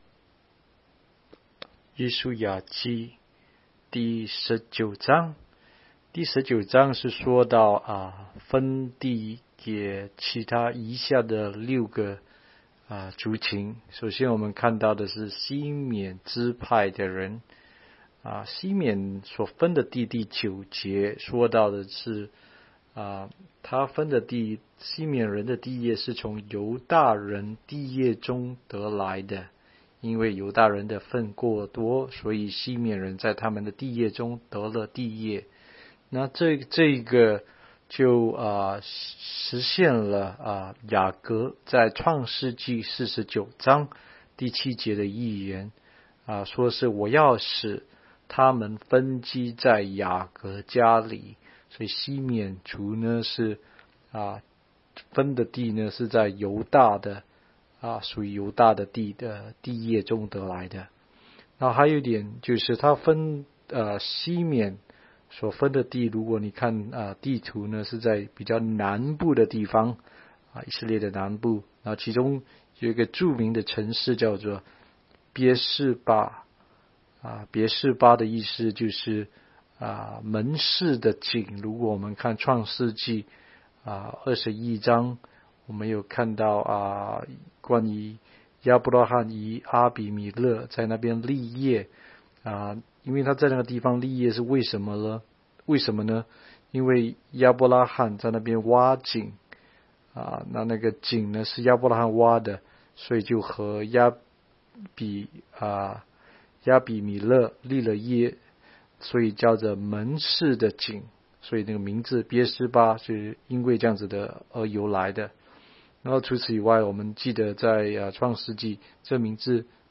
16街讲道录音 - 每日读经-《约书亚记》19章